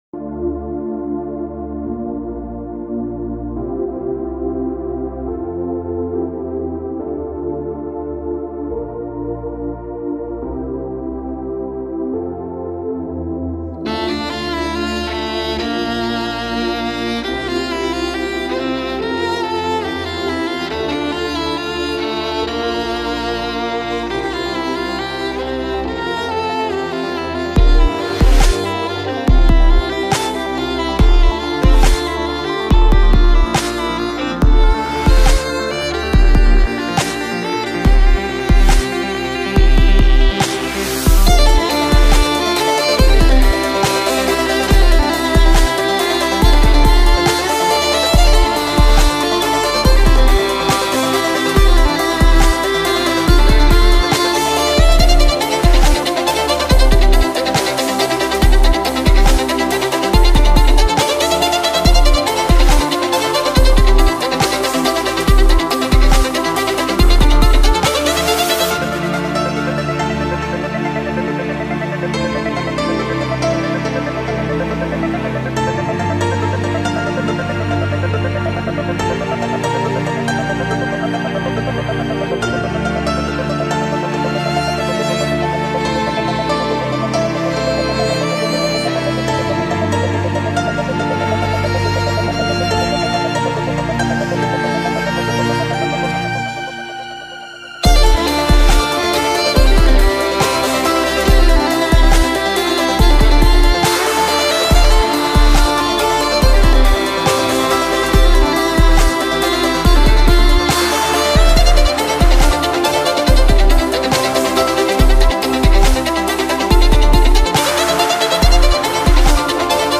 نوع آهنگ: لایت]